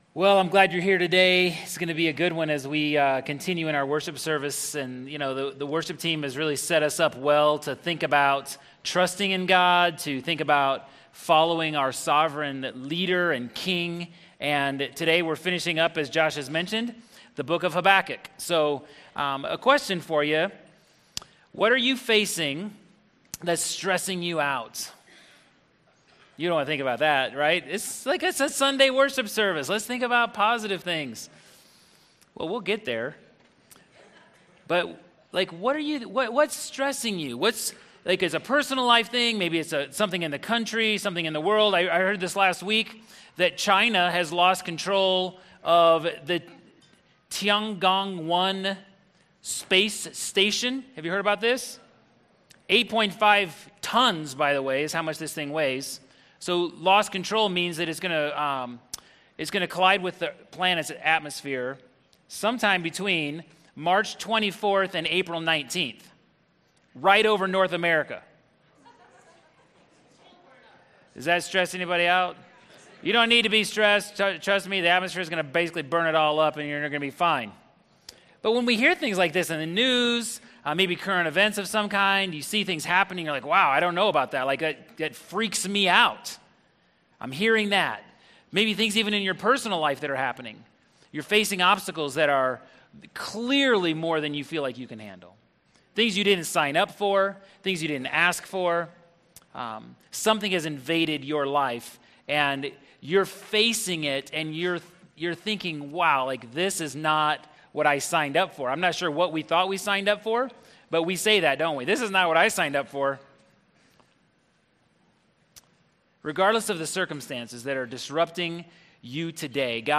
In the last sermon in Habakkuk, we’ll answer the question: how do you live by faith when you’re immersed in suffering and hardship?